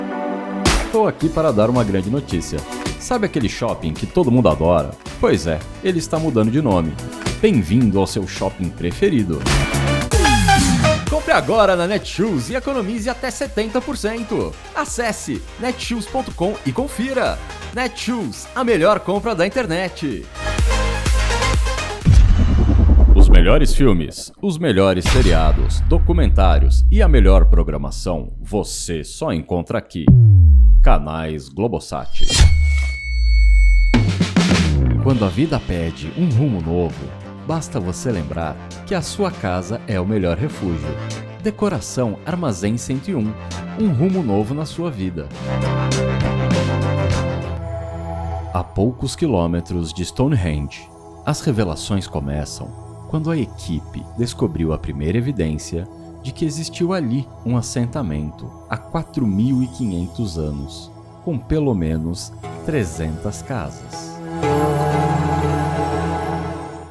Locução Publicitária